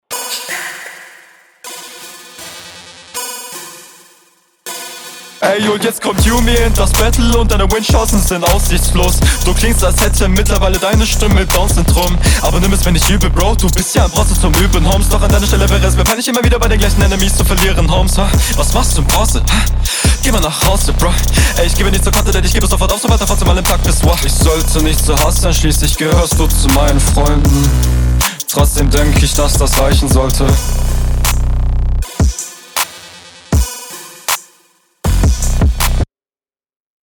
Oh nicer flow und guter Doubletime.